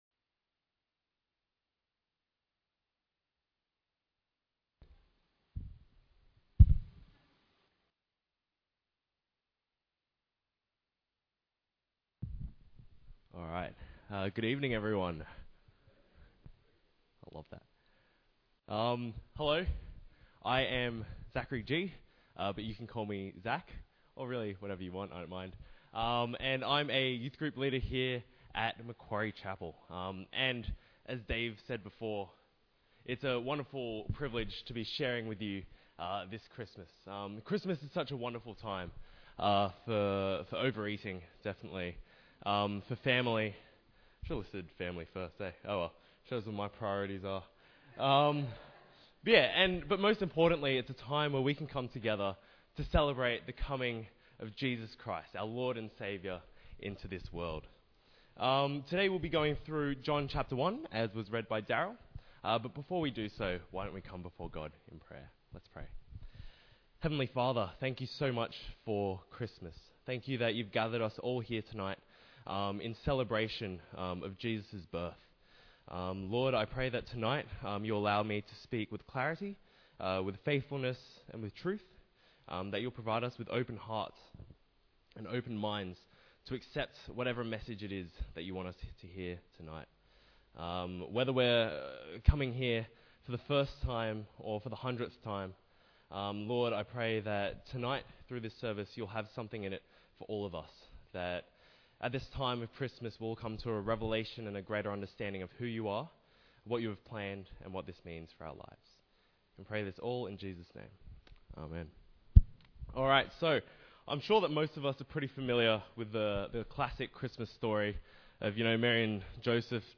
01-Christmas-Contemporoary-Lessons-Carols-John-1.mp3